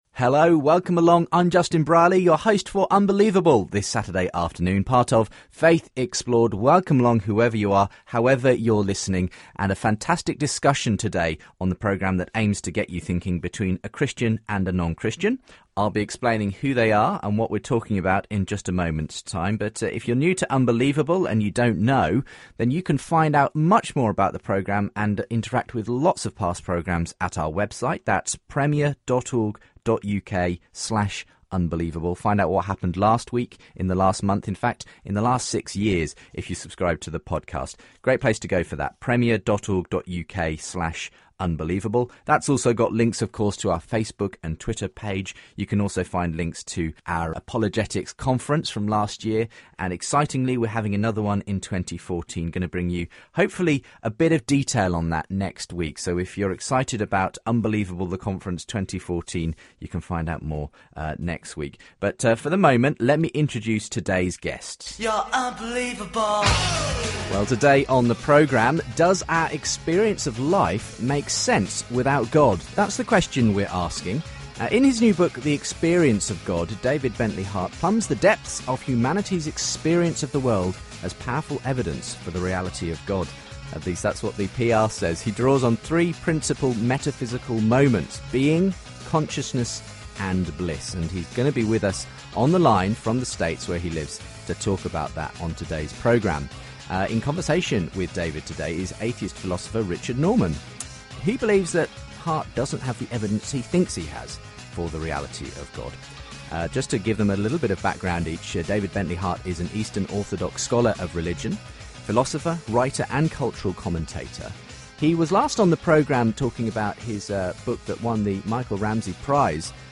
Christianity, Religion & Spirituality 4.6 • 2.3K Ratings 🗓 23 June 2014 ⏱ 82 minutes 🔗 Recording | iTunes | RSS 🧾 Download transcript Summary David Bentley Hart is a renowned Eastern Orthodox scholar of religion who presents three approaches to the existence of God in his new book "The Experience of God".